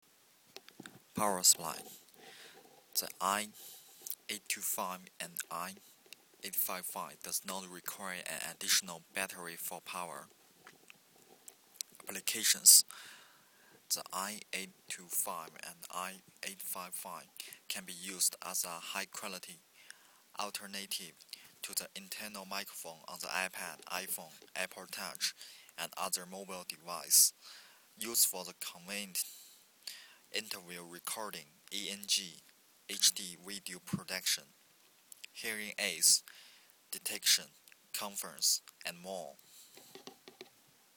i855 Human Voice
i855_Vocal_recording.wav